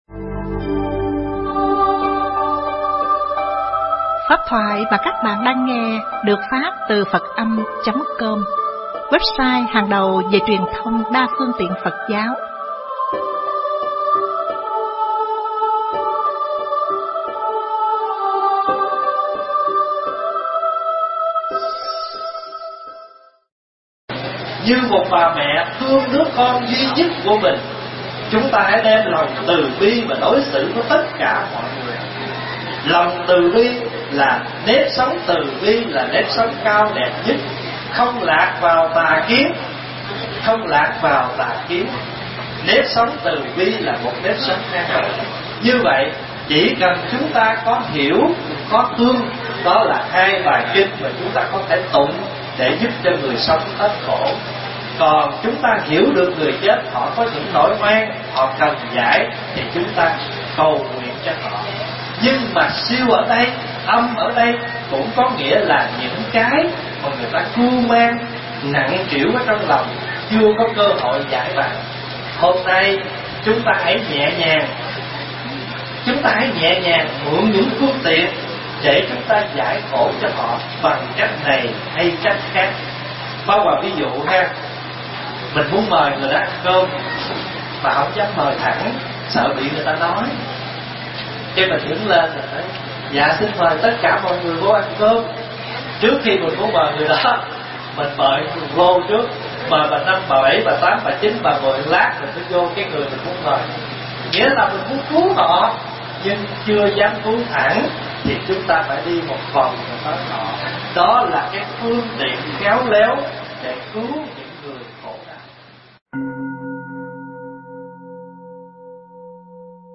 giảng tại Chùa Thanh Nguyên (Honolulu)